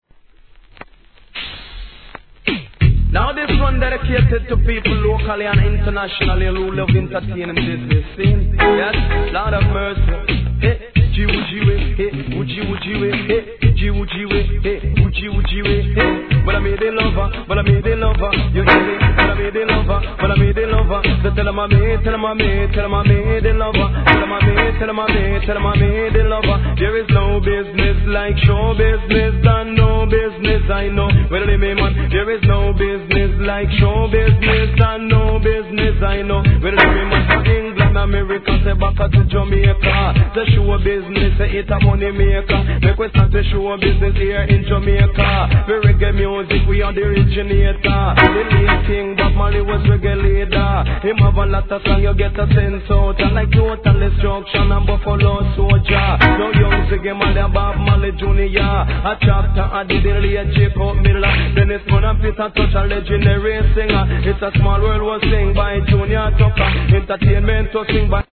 REGGAE
弾丸DeeJayスタイル!!